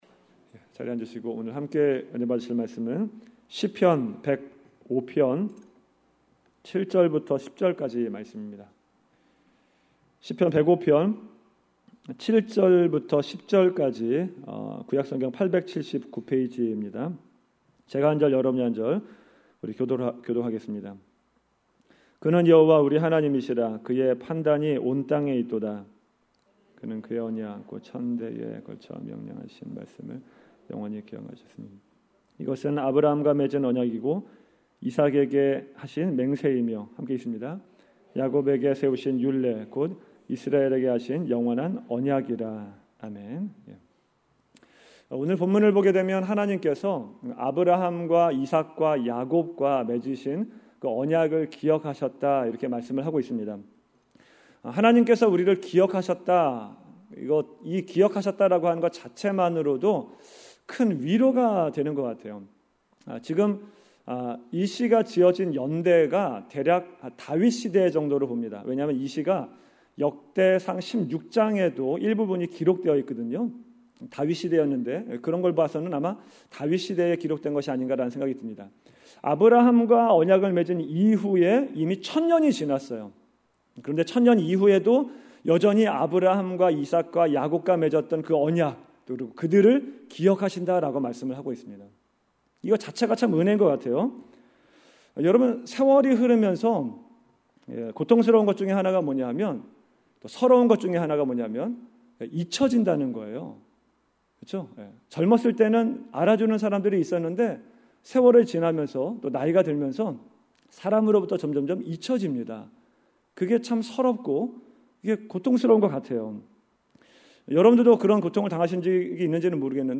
Categories: 2018년 금요설교 , Uncategorized